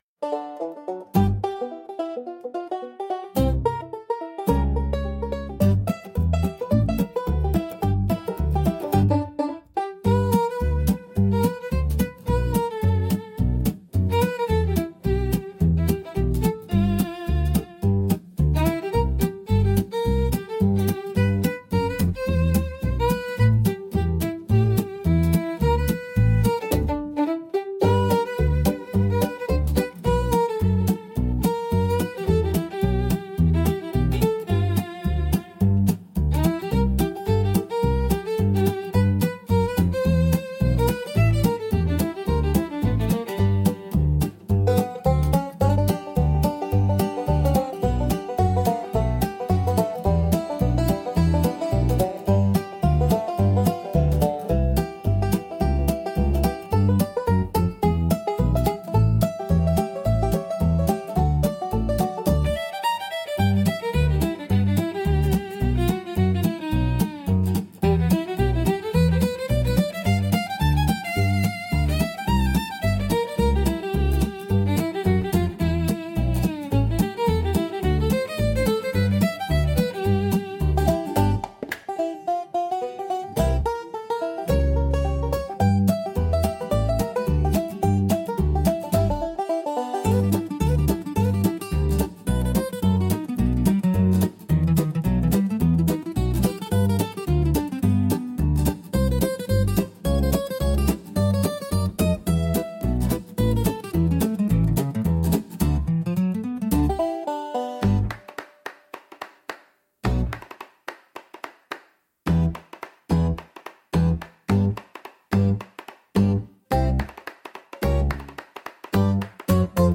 聴く人に親しみやすさと明るさを届け、ほのぼのとした楽しいシーンにぴったりのジャンルです。